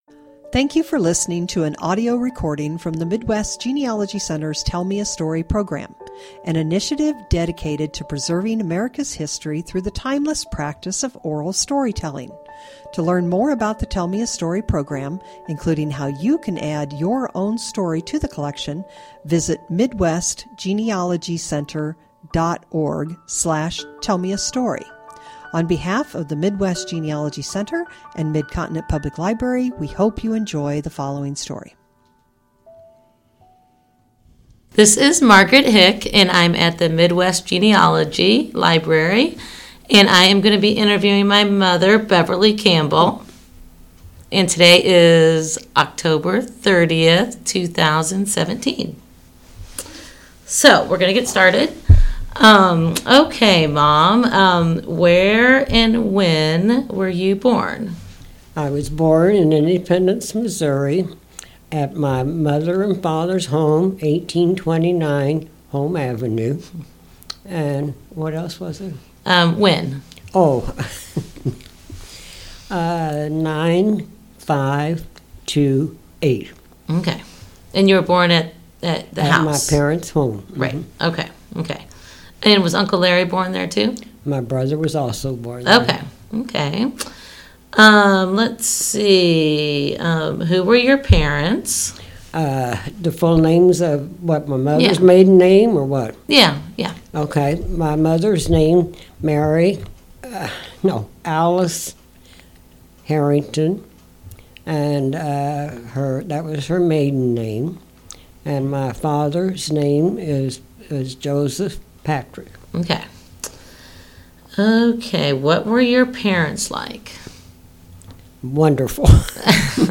Oral History Genealogy Family History